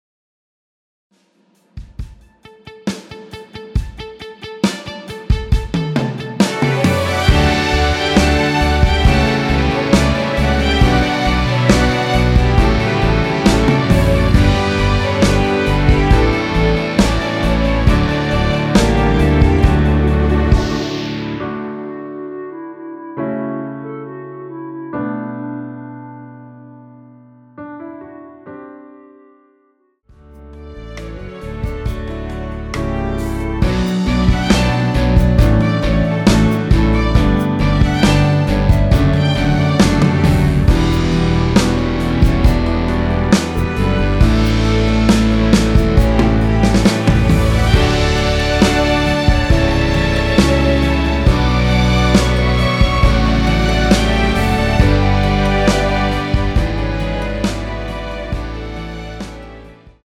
원키에서(-2)내린 멜로디 포함된 MR입니다.(미리듣기 확인)
앞부분30초, 뒷부분30초씩 편집해서 올려 드리고 있습니다.